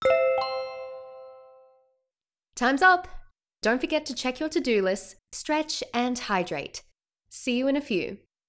break_alert_0.wav